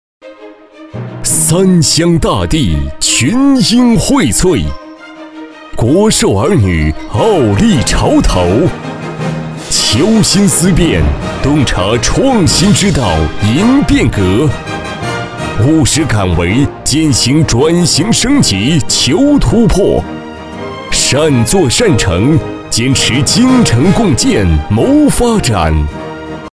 标签： 温柔